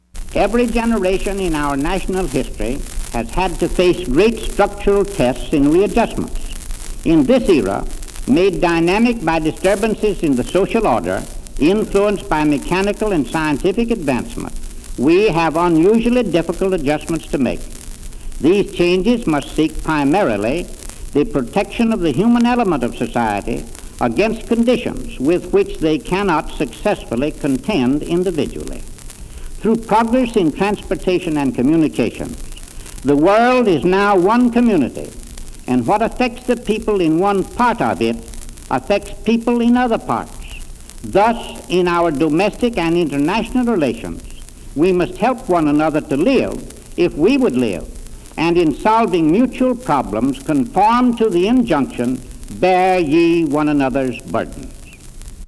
U.S. Secretary of Commerce Daniel Roper speaks about current events